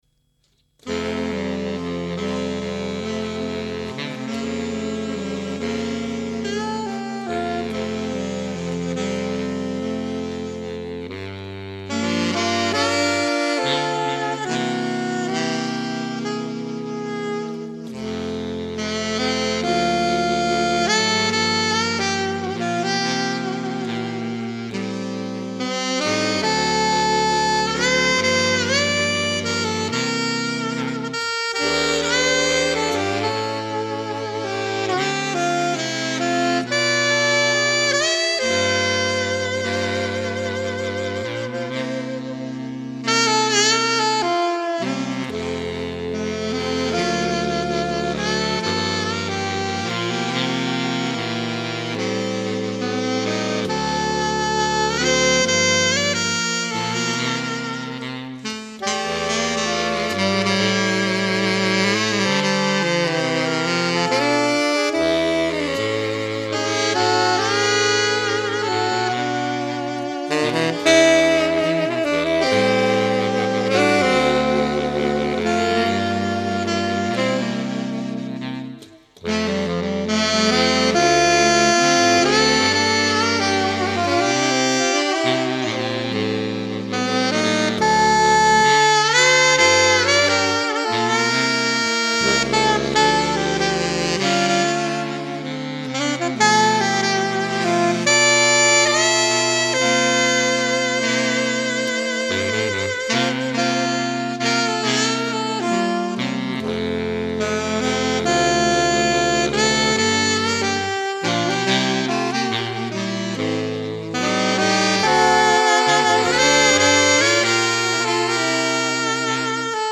More Saxophone Quartet Music